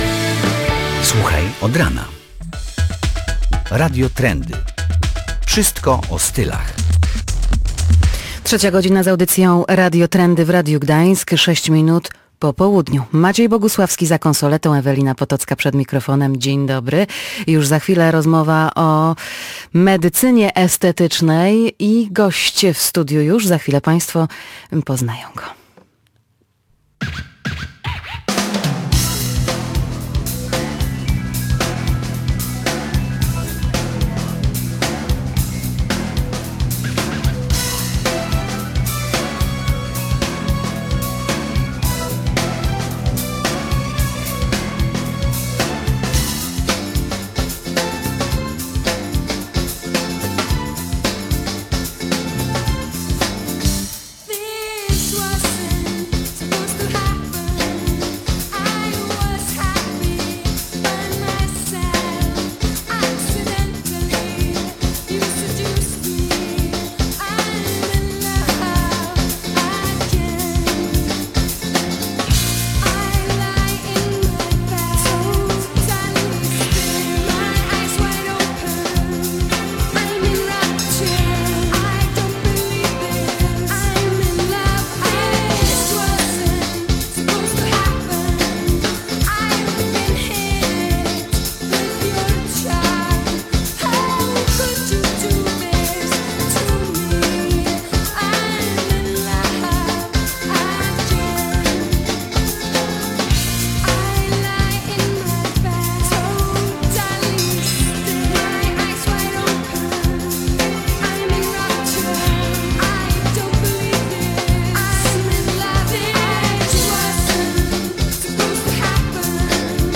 O to pytamy eksperta.